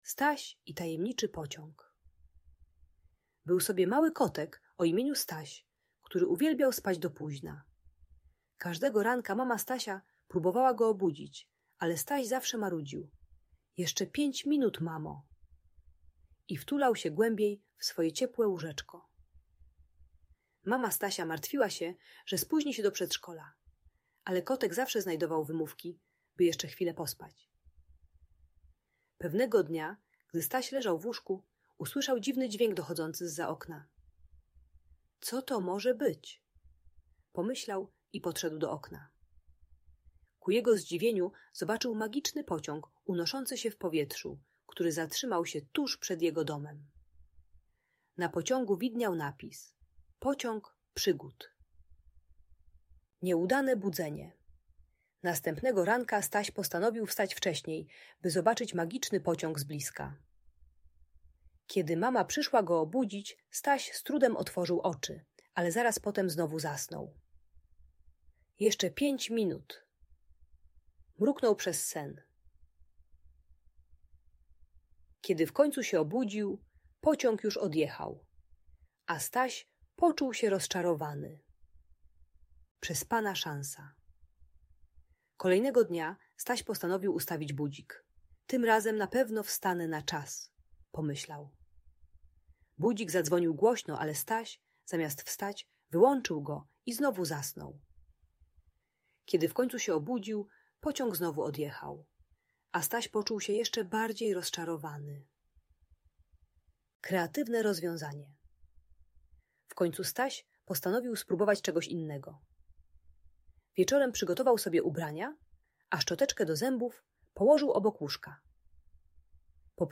Przygody Stasia: Opowieść o Magicznym Pociągu - Audiobajka dla dzieci